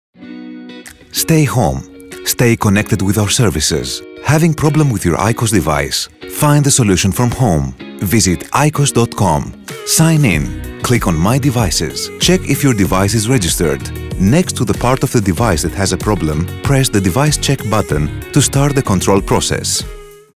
Fast, polished delivery in native Greek or English.
Commercial Demo
BassDeepLow
WarmCharming